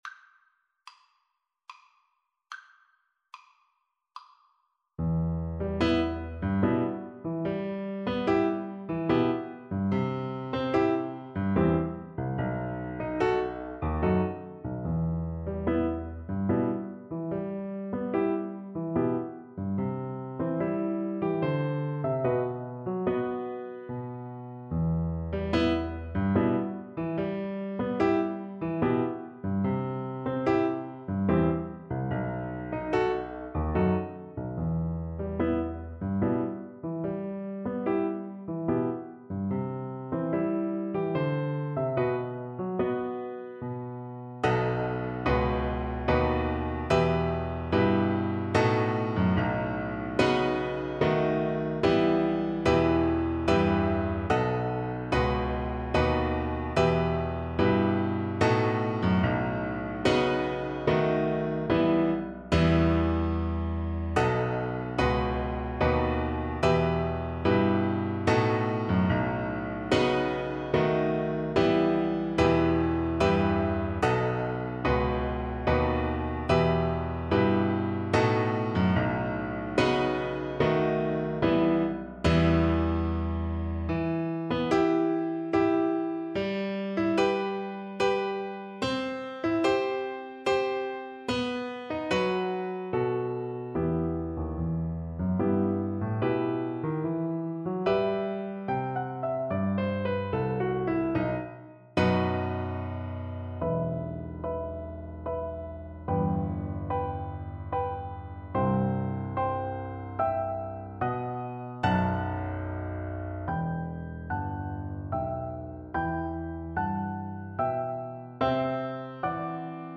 Violin
E minor (Sounding Pitch) (View more E minor Music for Violin )
3/4 (View more 3/4 Music)
G4-E7
Traditional (View more Traditional Violin Music)
Mora_Polskan_VLN_kar3.mp3